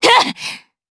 DarkFrey-Vox_Attack2_jp.wav